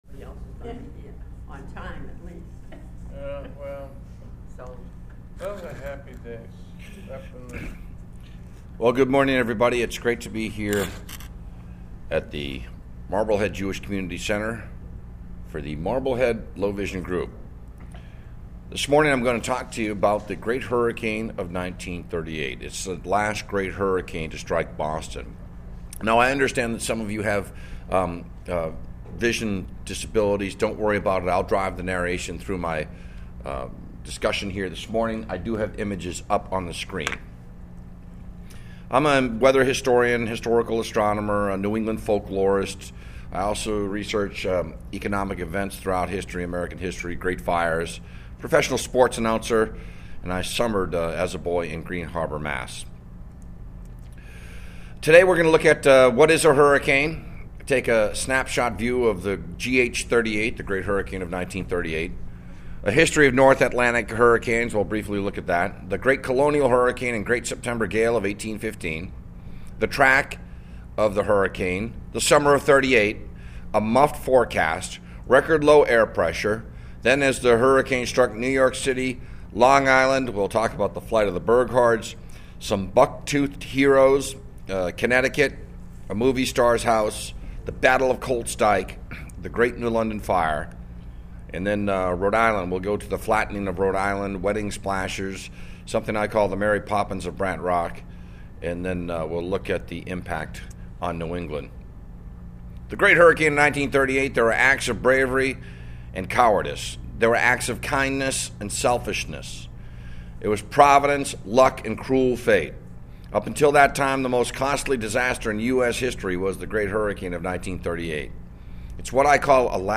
LECTURE AND SLIDE SHOW